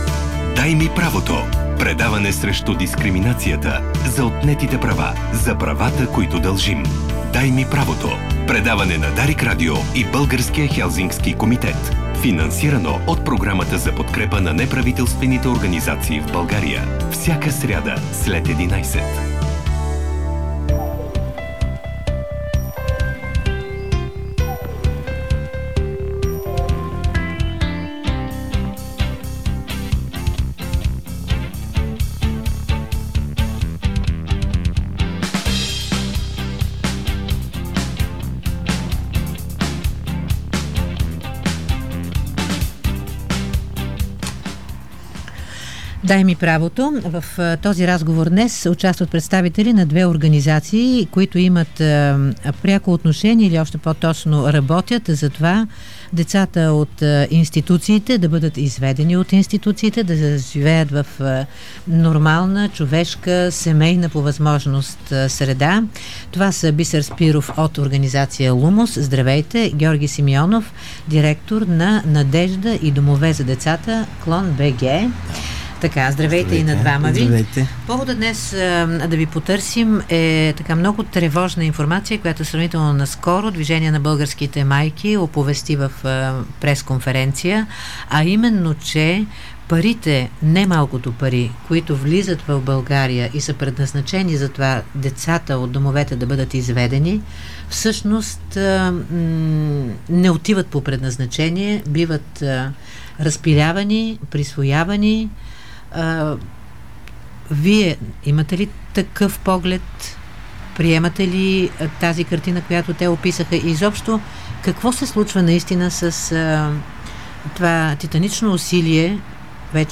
Рубриката "Дай ми правото" Назад Кой Говори? 10.06.2015 | Интервю Кой Говори? изтегли Чуй още Още от мрежата Какво можем да очакваме в група за взаимопомощ out Източник: Дарик Радио АД